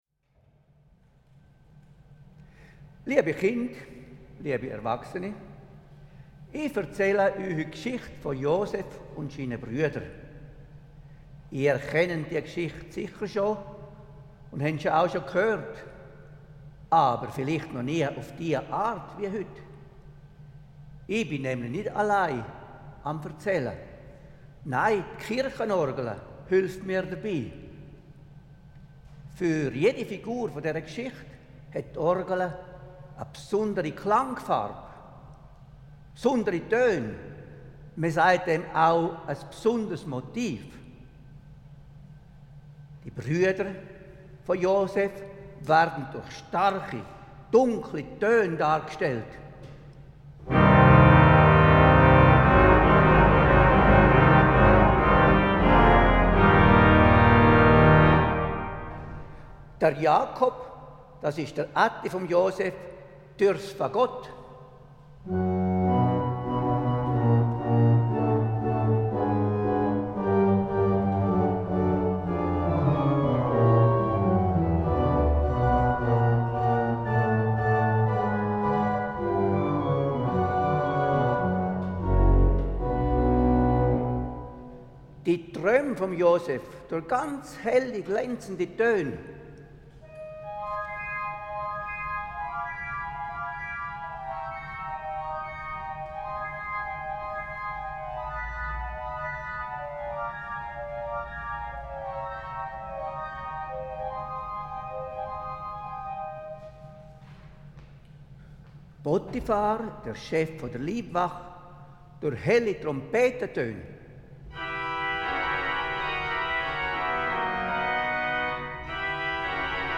in der reformierten Kirche St. Johann in Schiers
Orgel